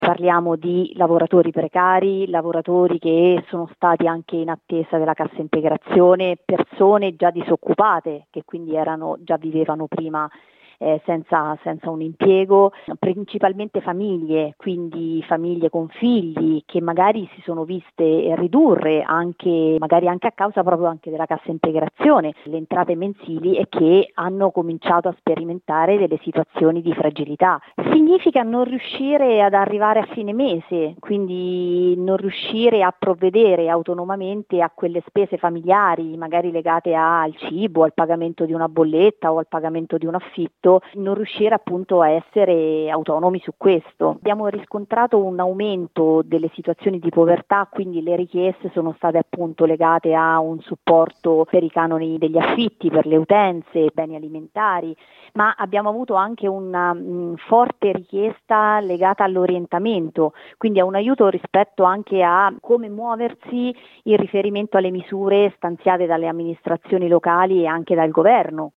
sociologa